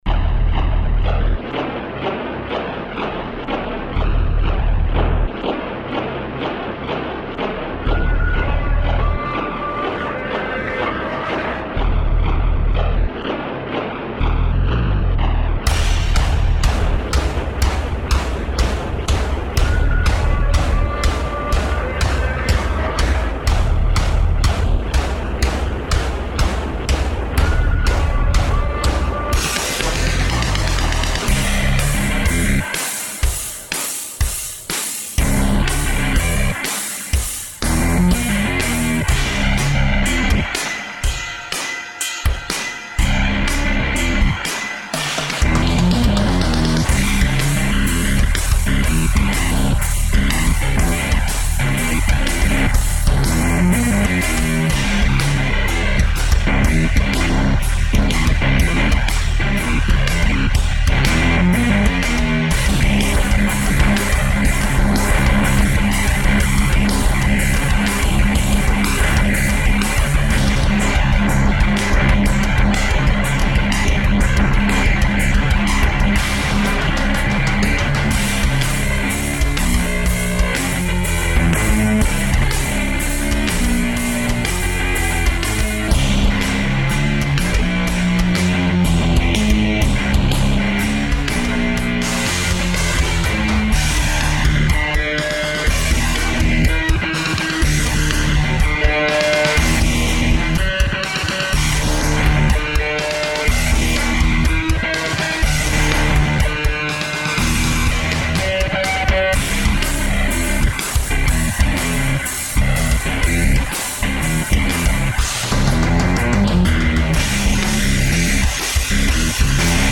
But slightly morphed version with a heavier tune (below).
That versionmakes my ears feel weird...